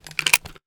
metahunt/weapon_foley_pickup_08.wav at 36da1f016f007c7dd53ec88945f1a6c3dd9d7be5
weapon_foley_pickup_08.wav